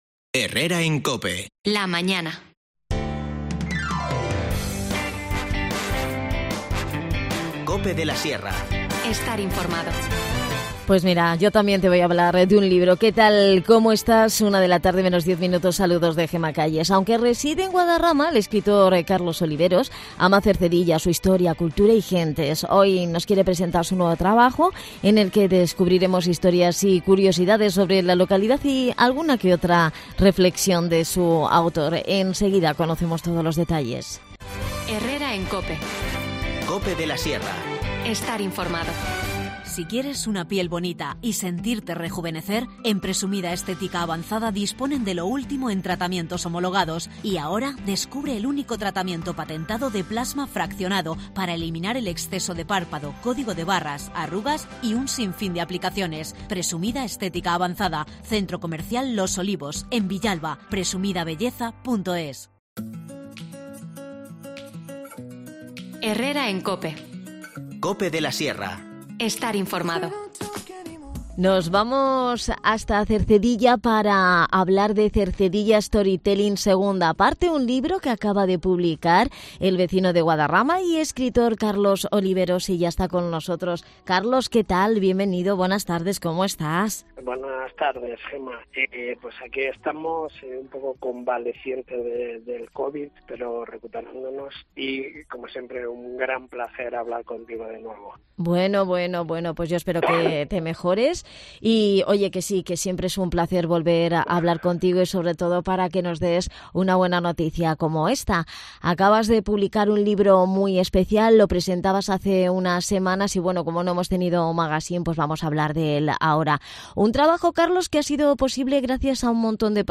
INFORMACIÓN LOCAL
Las desconexiones locales son espacios de 10 minutos de duración que se emiten en COPE, de lunes a viernes.